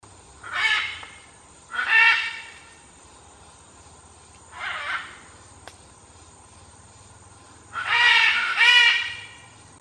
Military Macaw (Ara militaris)
Life Stage: Adult
Location or protected area: Parque Nacional Amboró
Condition: Wild
Certainty: Photographed, Recorded vocal